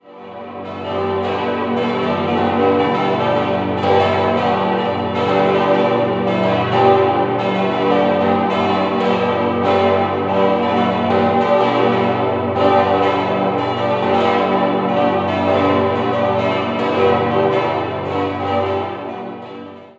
In den Jahren 1786/87 wurde die heutige reformierte Kirche mit ihrem markanten Turm errichtet. 6-stimmiges Geläute: g°-h°-d'-e'-fis'-a' Die Glocken stammen aus der Gießerei Rüetschi in Aarau und wurden 1953 gegossen.